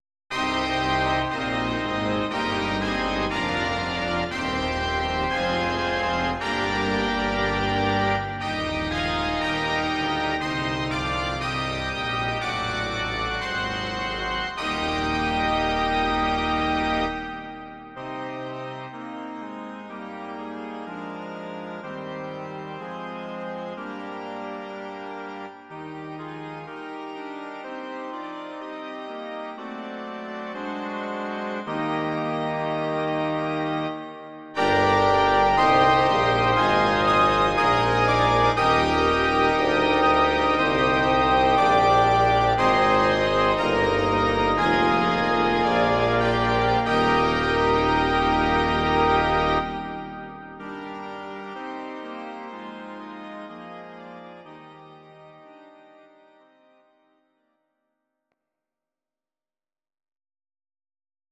These are MP3 versions of our MIDI file catalogue.
Your-Mix: Instrumental (2074)